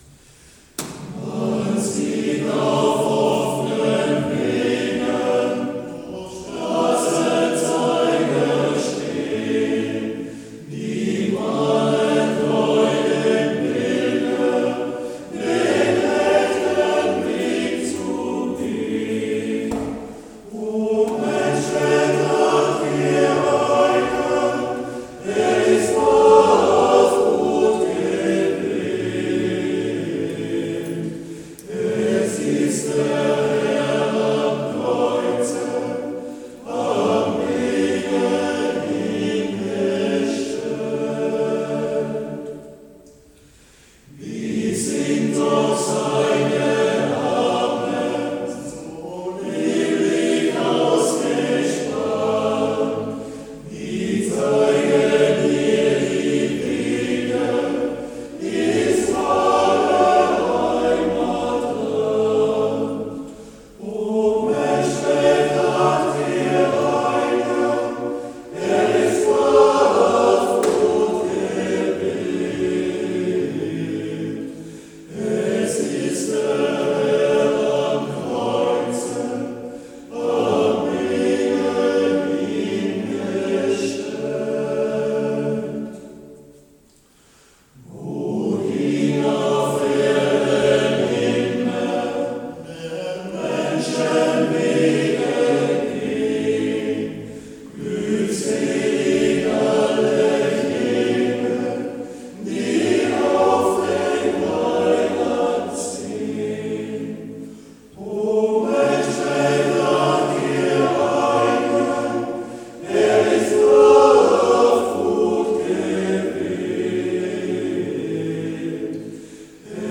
Am 6. August 2023 fand in der Filialkirche Nussberg die Festmesse zum Patrozinium des heiligen Oswald statt.
Für die stimmungsvolle musikalische Umrahmung sorgte traditionsgemäß die Sängerrunde Obermühlbach
Ihr Gesang wurde mit reichlichen Applaus belohnt.